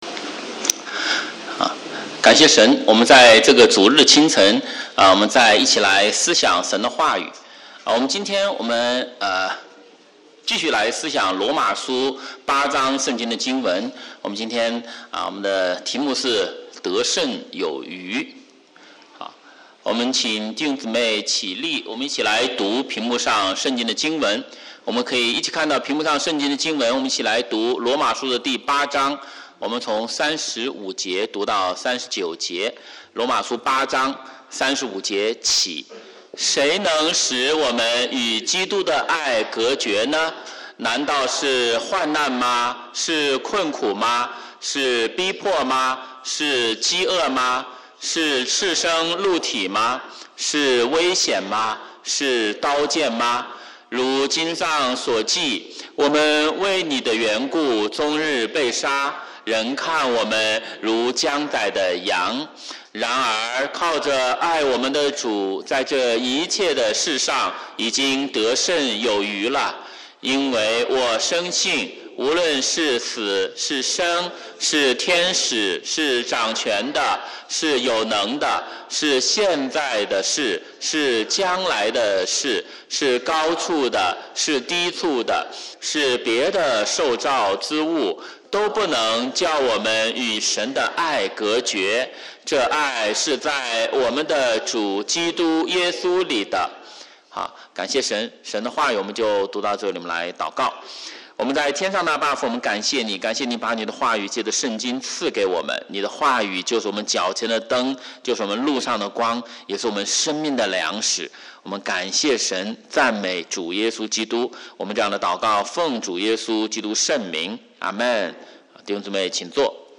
华语主日崇拜讲道录音